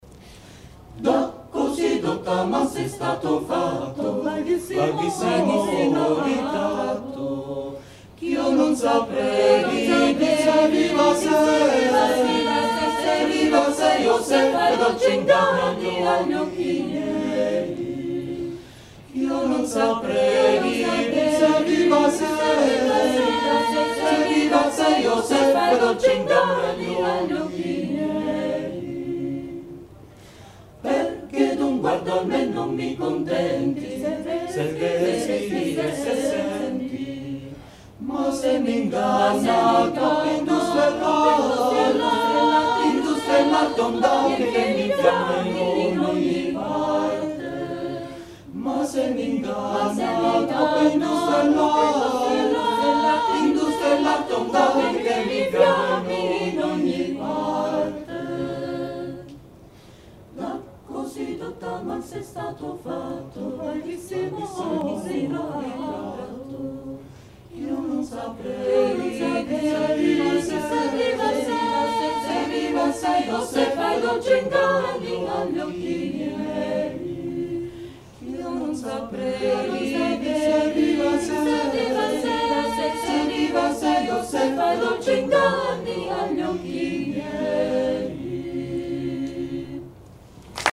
Palestrina was a prolific composer, writing mainly sacred music such as Masses, motets, and Magnificats, in the a cappella style (no instrumental accompaniment), as well as both secular and sacred madrigals; the pinnacle of his achievement is in his Masses, of which he wrote more than one hundred.
live recordings for Concert FM.